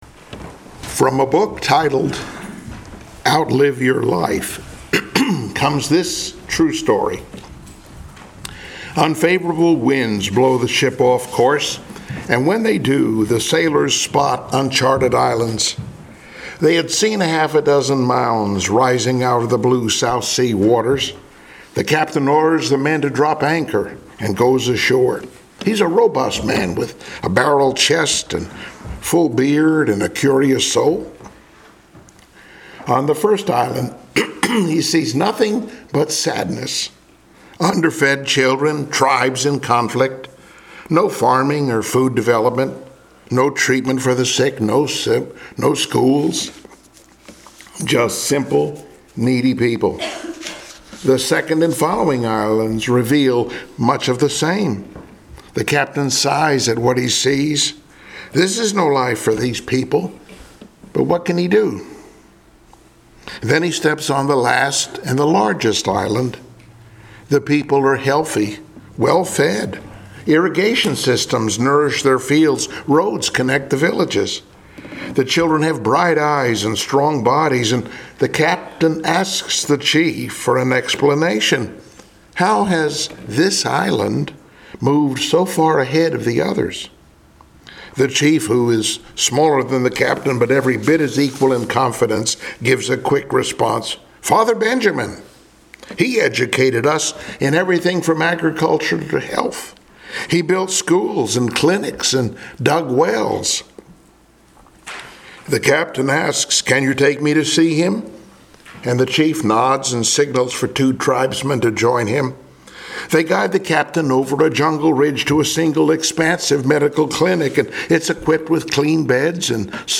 Passage: Acts3:1-10 Service Type: Sunday Morning Worship « “Job